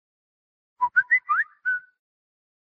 Toque do assobio WhatsApp original
Toque WhatsApp Original Toque de notificação do WhatsApp
Descrição: Baixe o toque original do assobio WhatsApp em mp3 para o seu celular aqui. Você pode usar esse som para configurá-lo como toque de notificação ou para zoar seus amigos, dizendo que o WhatsApp tem uma nova mensagem.